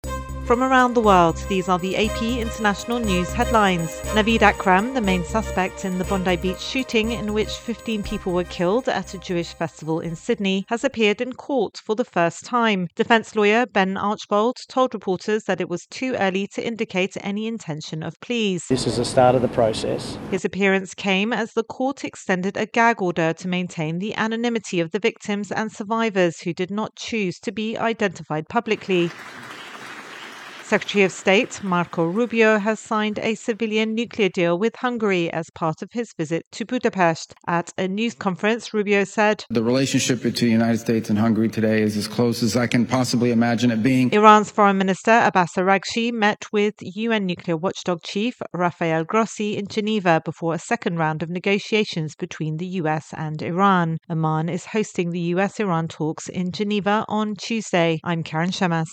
Latest AP headlines from around the world